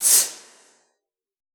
Index of /90_sSampleCDs/Best Service - Extended Classical Choir/Partition I/CONSONANTS